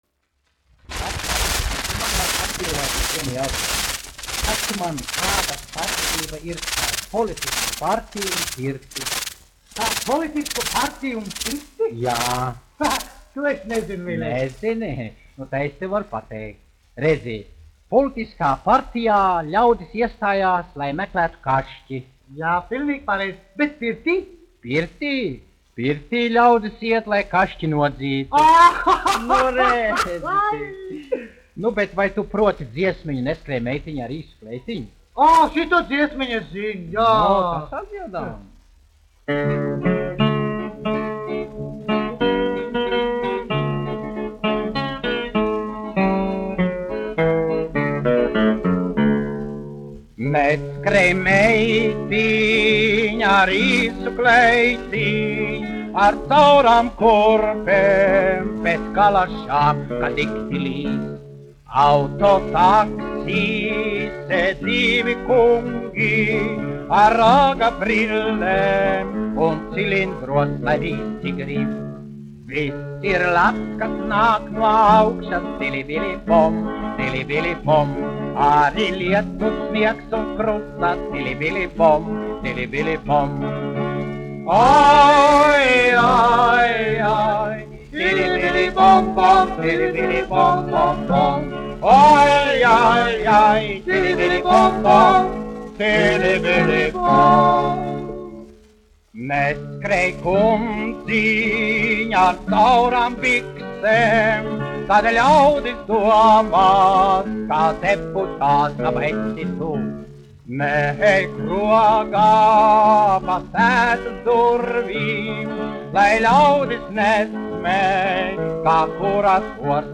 1 skpl. : analogs, 78 apgr/min, mono ; 25 cm
Humoristiskās dziesmas
Ieraksta sākumā bojāta skaņa